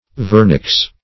vernix - definition of vernix - synonyms, pronunciation, spelling from Free Dictionary
vernix.mp3